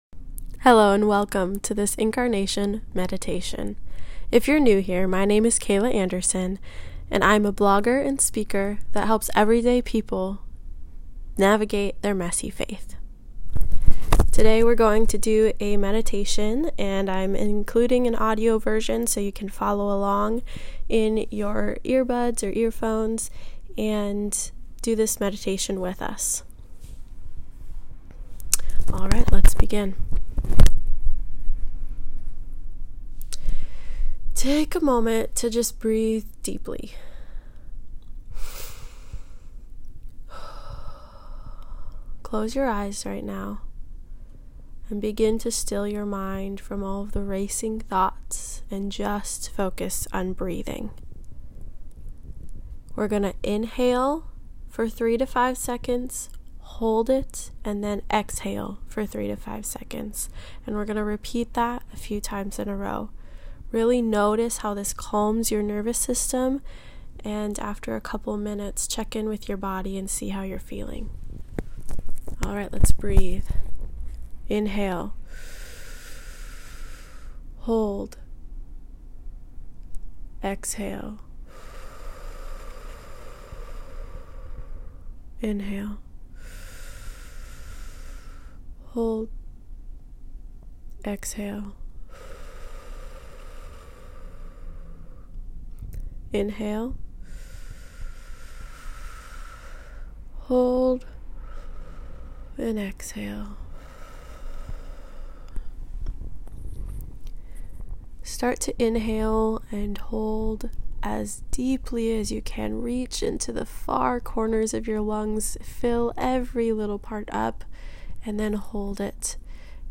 If you've been rushing around this Christmas season, take a moment to pause and practice the presence of God with this audio devotional.
Incarnation-meditation.m4a